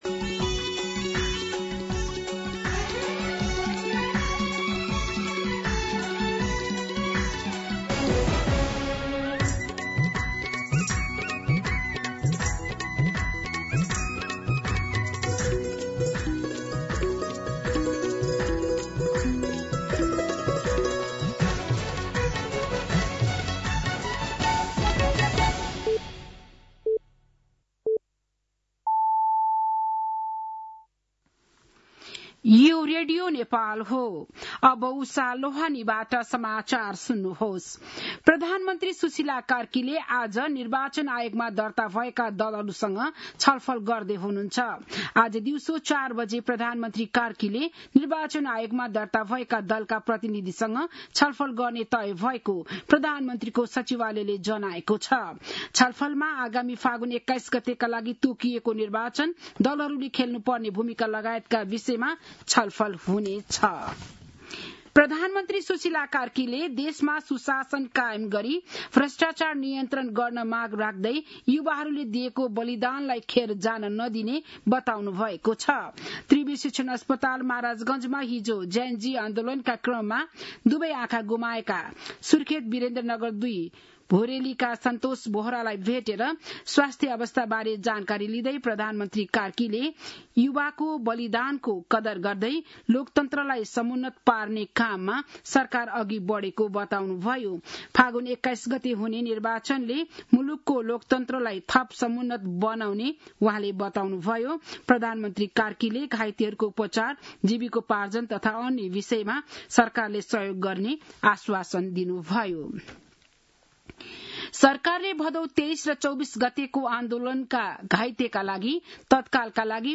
बिहान ११ बजेको नेपाली समाचार : ३ मंसिर , २०८२
11-am-Nepali-News-3.mp3